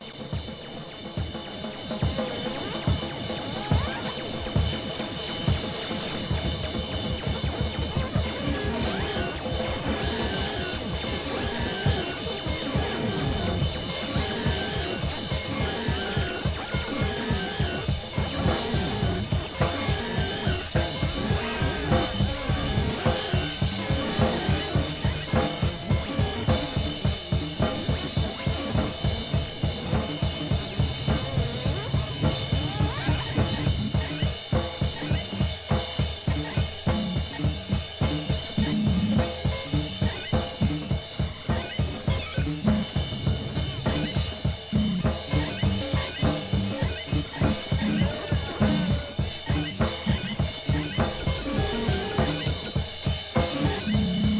エレクトロと生ドラムのジャムセッション! Experimental, FREE JAZZ SIDE A 1.